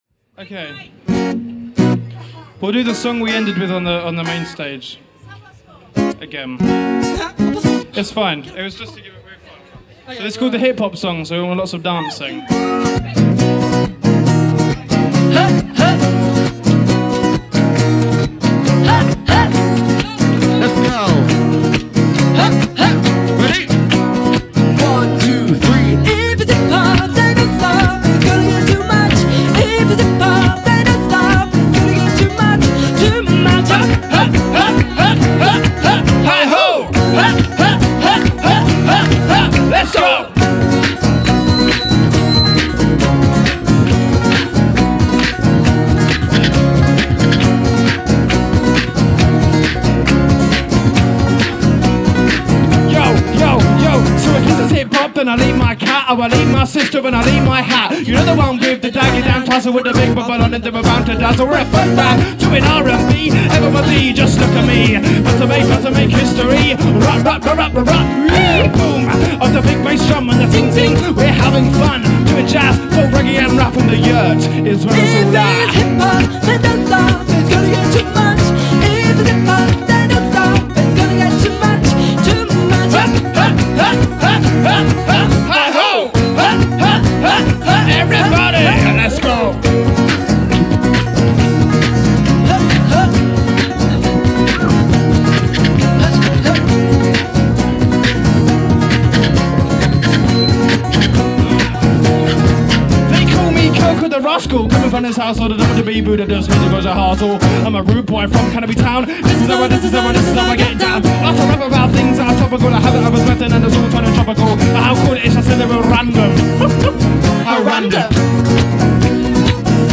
Recorded Live at Tentertainment 2012